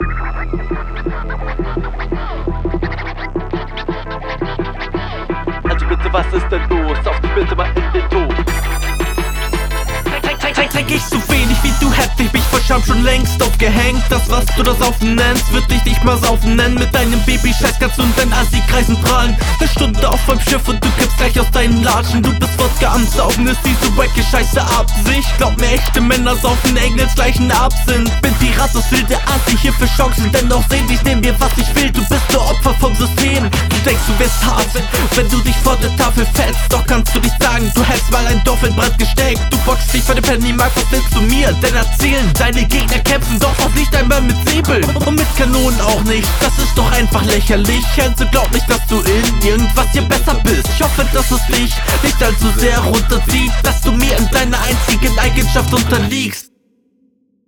wie bitte? das klingt sehr matschig. reime am anfang sind ästhetisch. mehr davon! absinth zu …